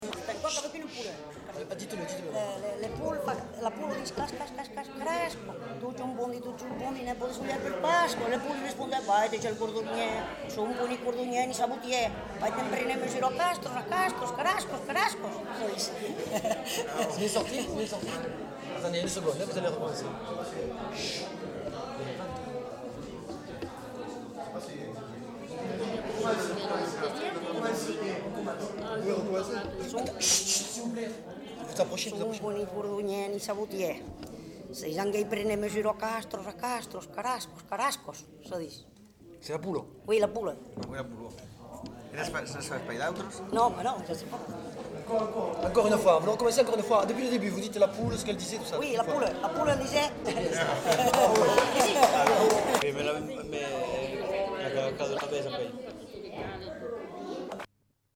Aire culturelle : Lauragais
Lieu : Lanta
Genre : forme brève
Type de voix : voix de femme
Production du son : récité
Classification : mimologisme